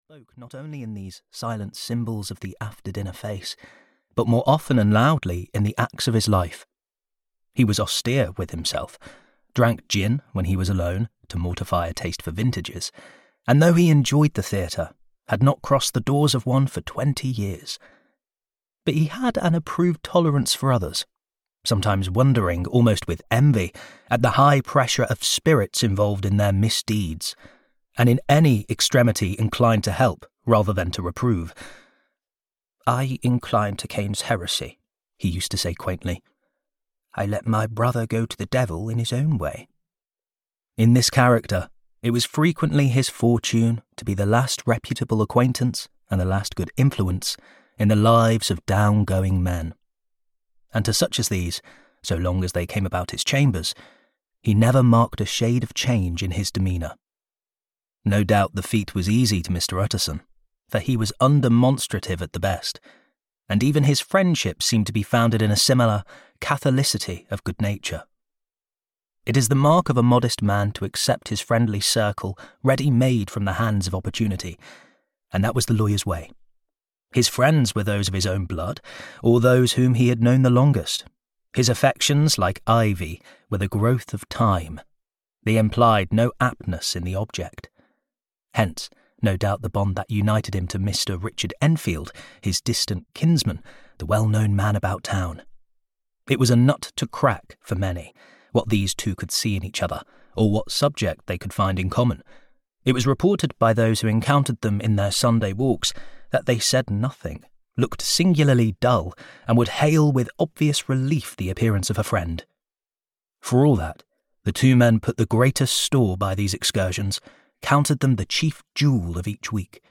Audio knihaThe Strange Case of Dr Jekyll and Mr Hyde (EN)
Ukázka z knihy